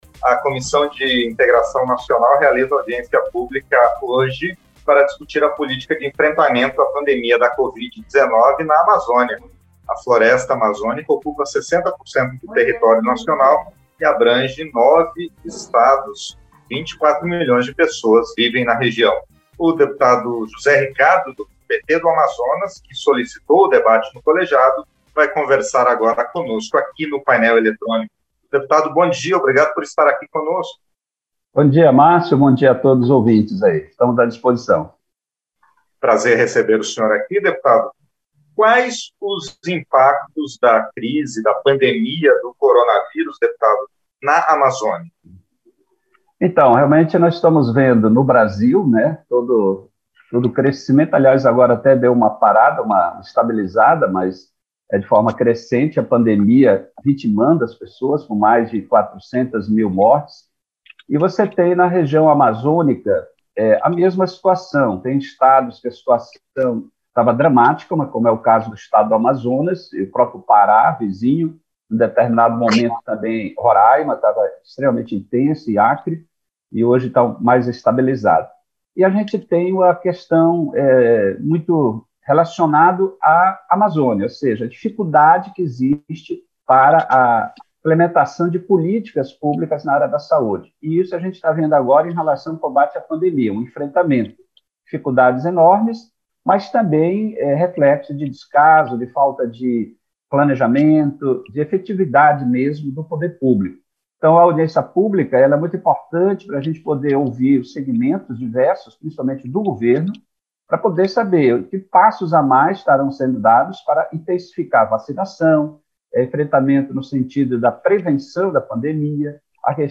Entrevista - Dep. José Ricardo (PT-AM)